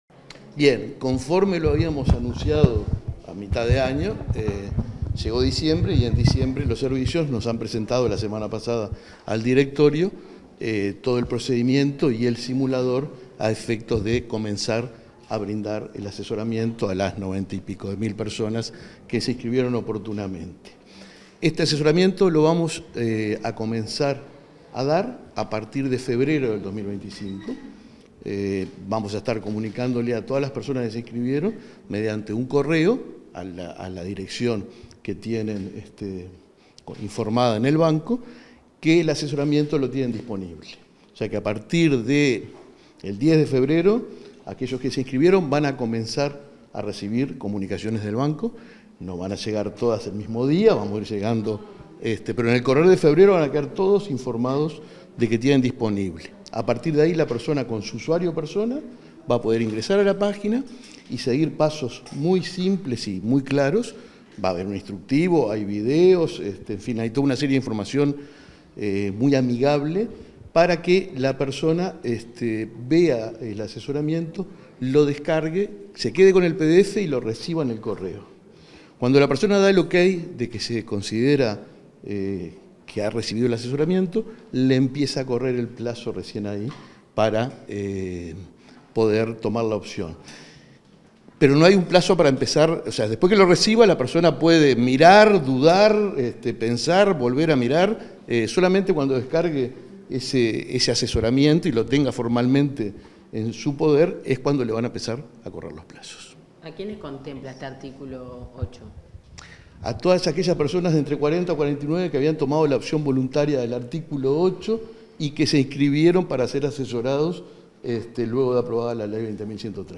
Declaraciones del presidente del BPS, Alfredo Cabrera
cabrera prensa.mp3